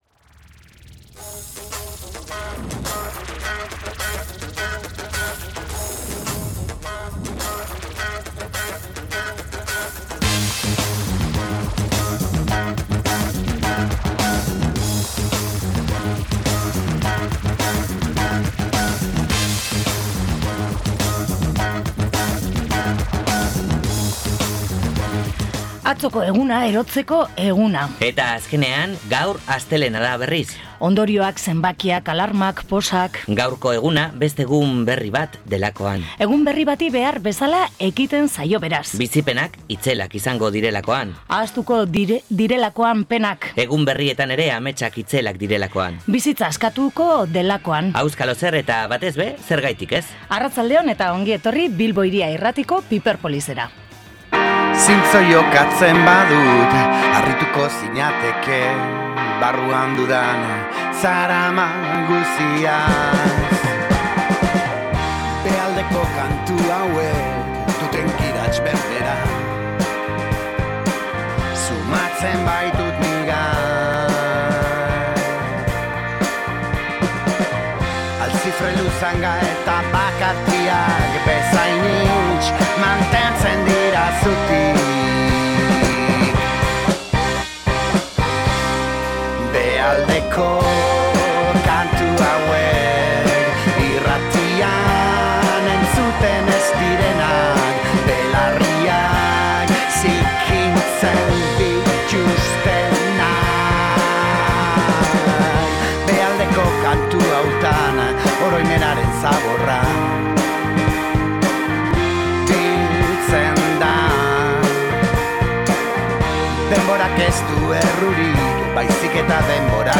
Sirokaren musikaz gain Joseba Sarreonandiaren Gartzelako poemak liburuaren inguruko poesia erresitaldia entzun dugu. saioa deskargatzeko klikatu HEMEN.